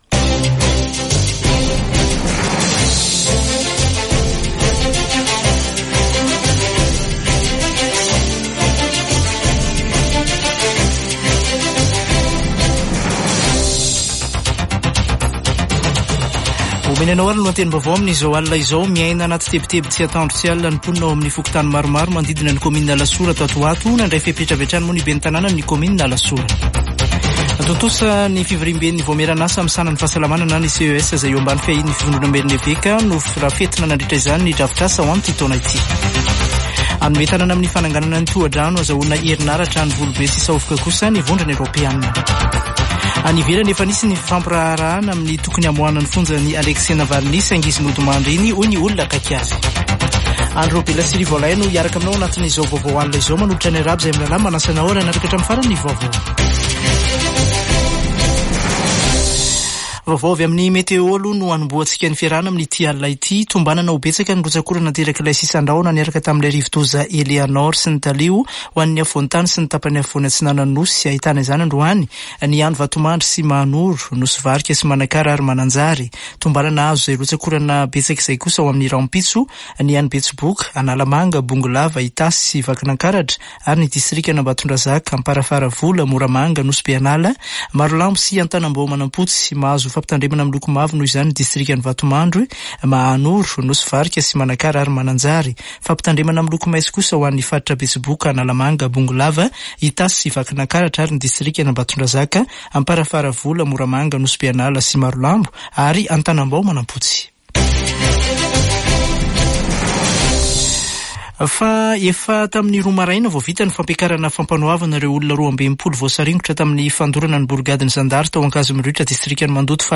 [Vaovao hariva] Alatsinainy 26 febroary 2024